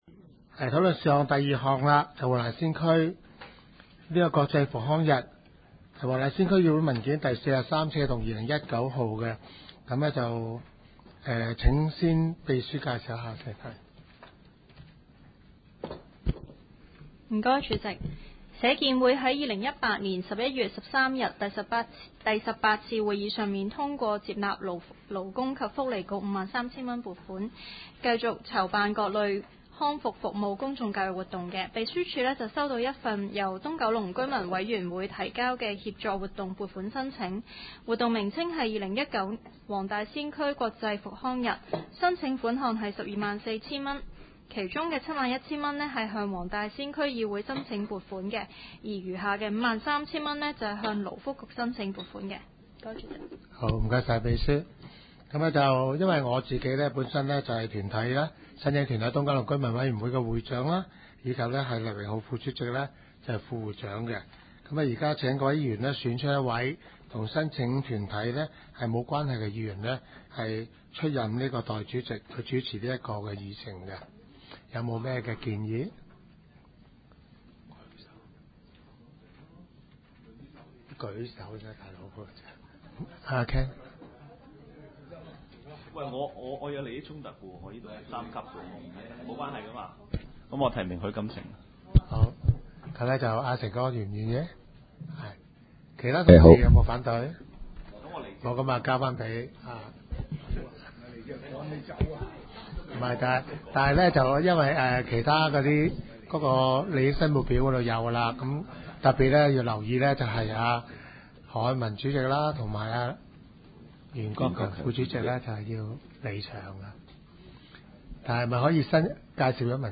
区议会大会的录音记录
黄大仙区议会第二十三次会议
黄大仙区议会会议室